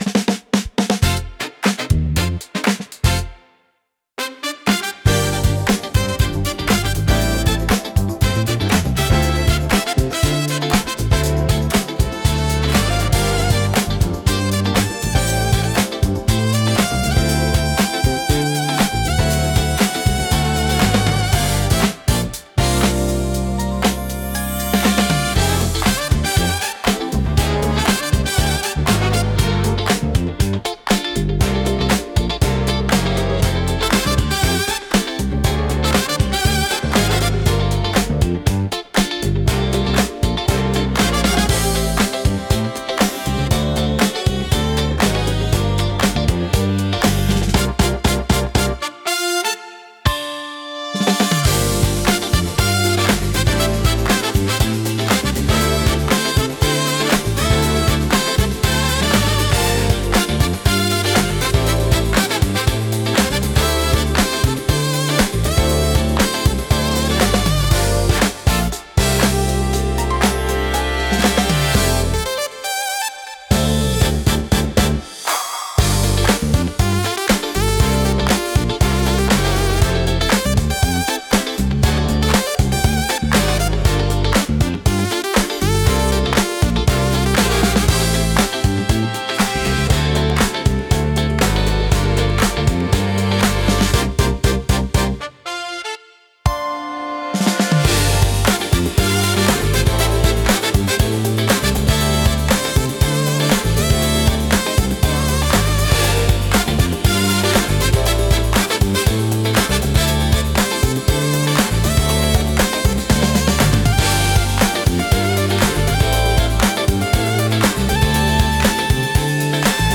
躍動感と活気に満ちたジャンルです。